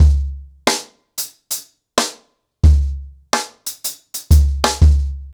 CornerBoy-90BPM.21.wav